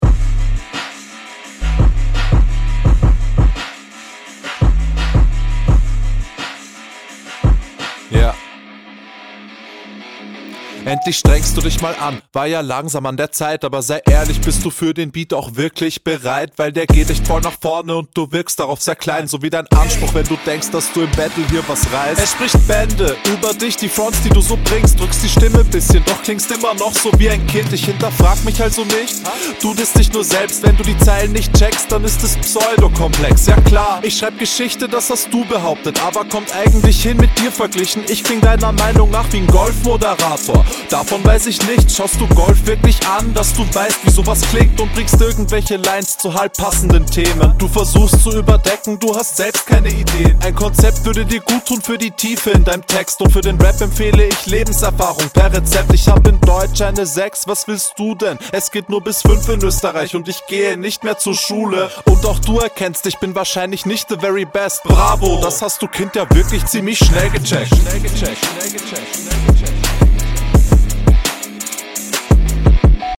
beat immernoch hard scheiße, wollt ich nur nochmal hier sagen, ja alles gekontert und gyle …